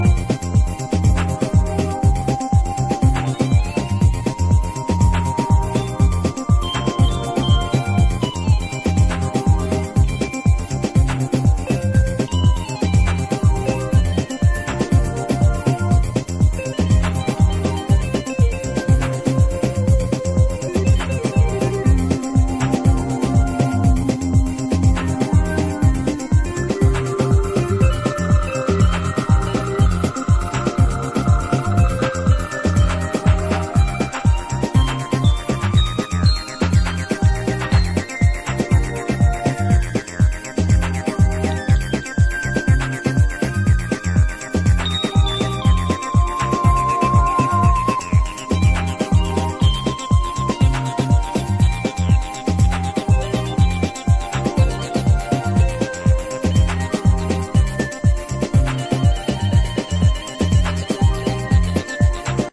Techier beats and a moody bassline ar... more...
House